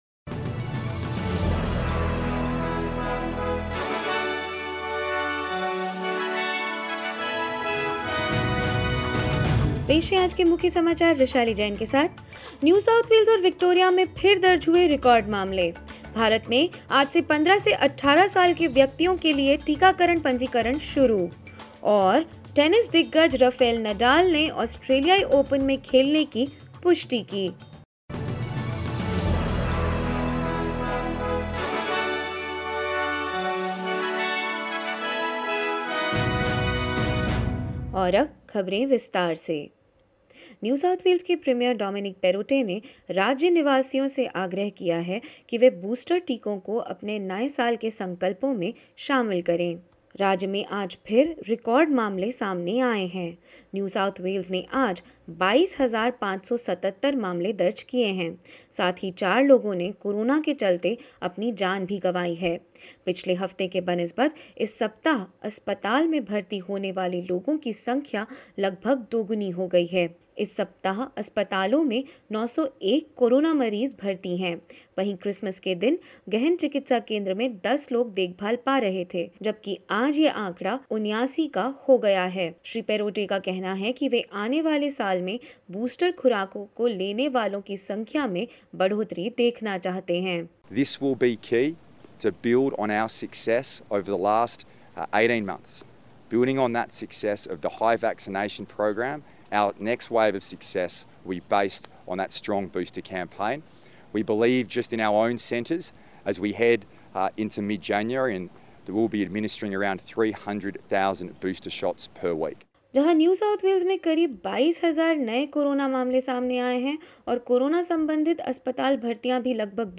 In this latest SBS Hindi News bulletin: NSW records more than 22,000 cases, Victoria almost 8,000 on the New Year's Day; In India, vaccination for 15 to 18 year olds to begin on 3 January and more news.